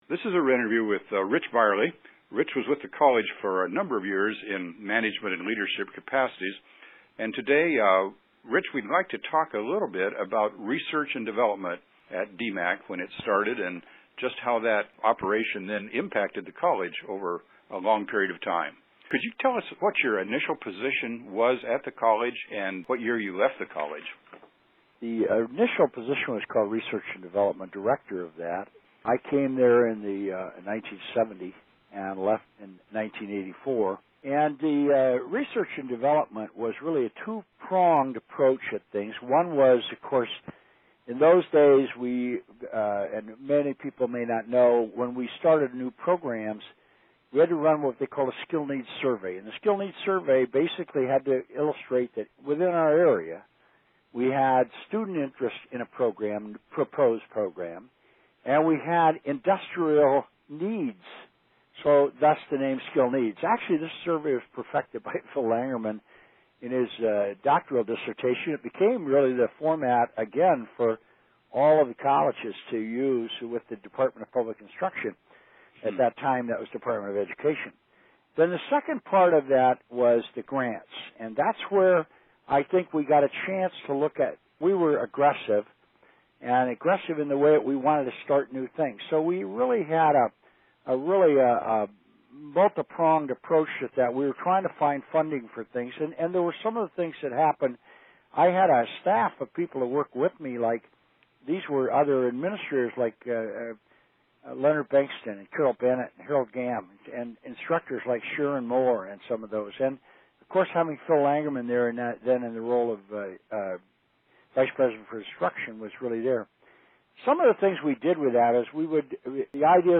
Interviews
Listen to conversations with former DMACC employees by clicking an audio player below.